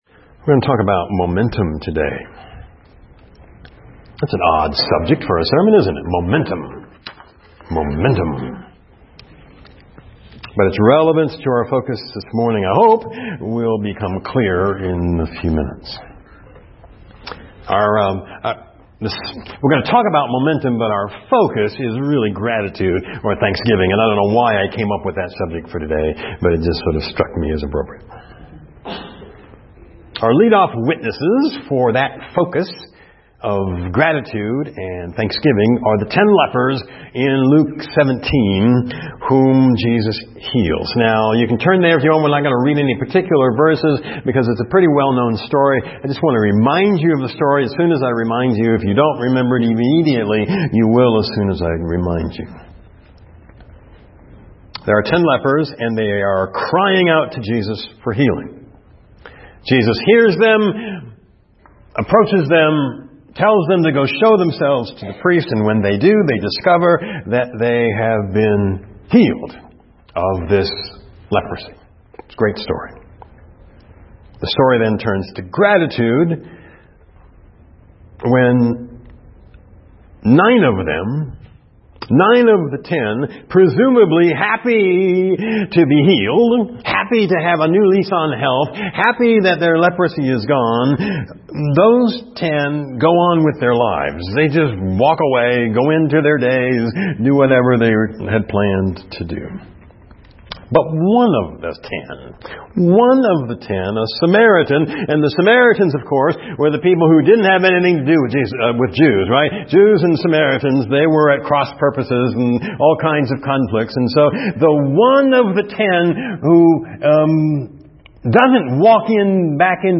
FCCEM Sermon Audio Files - First Christian Church of East Moline